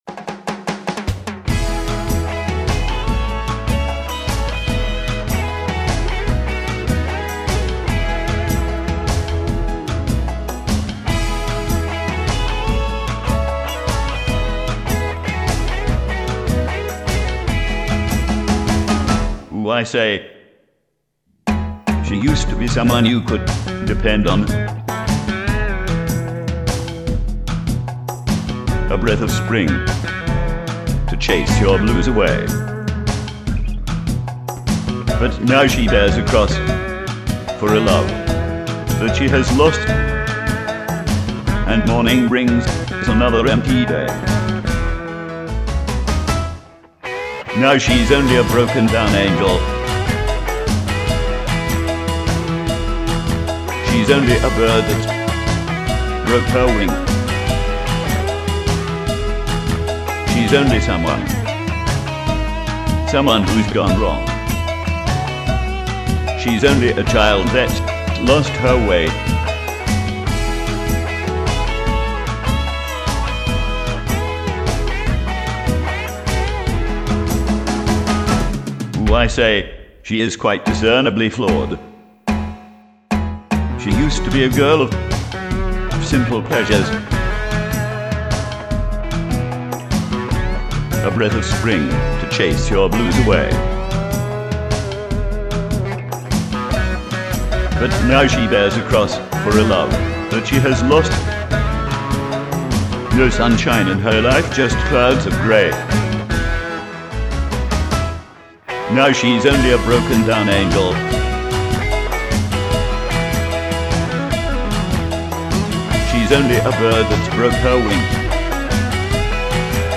ScotsRock